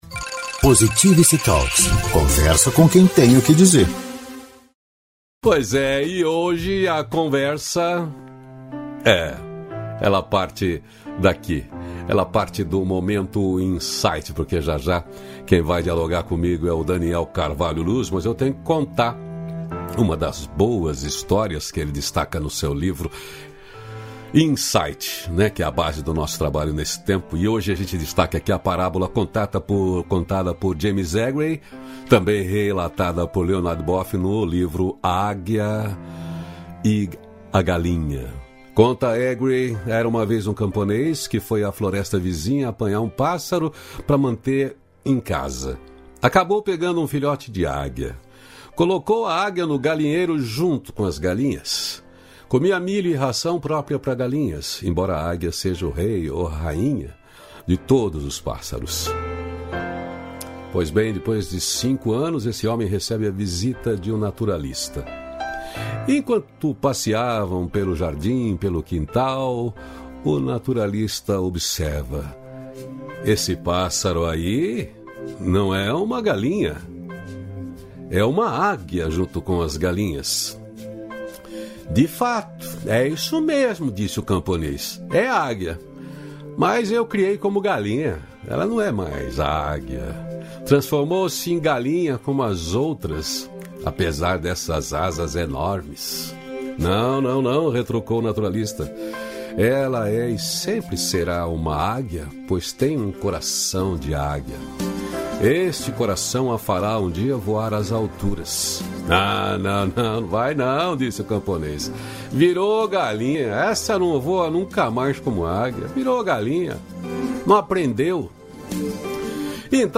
A Águia e a Galinha – Momento Insight Ao Vivo
285-feliz-dia-novo-entrevista.mp3